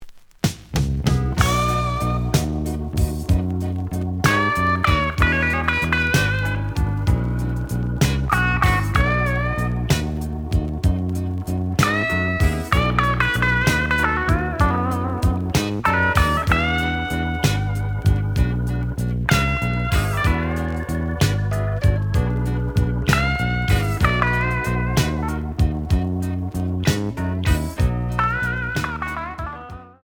The audio sample is recorded from the actual item.
●Genre: Blues